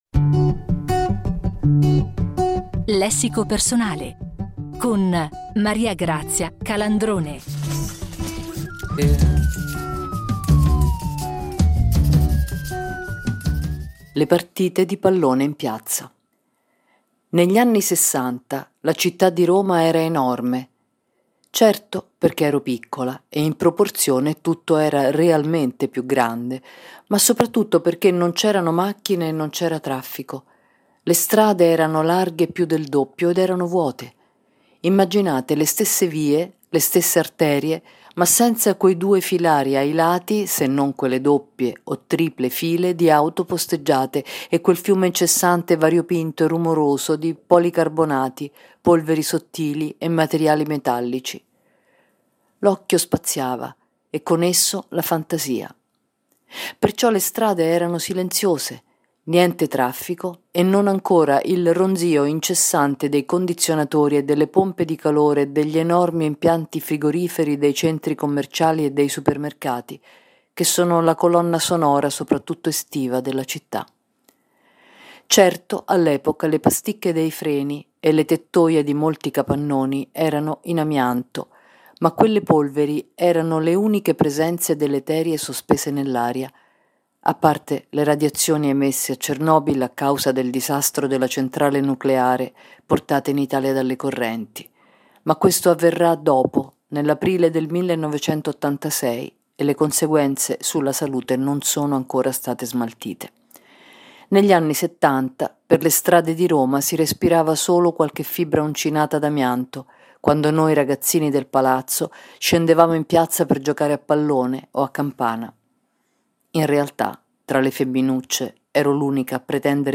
Questa settimana, a ripercorrere la sua infanzia e cinque ricordi cruciali ad essa legati, è Maria Grazia Calandrone una delle voci più intense e originali della poesia e della narrativa italiana contemporanea.